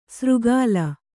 ♪ sřgāla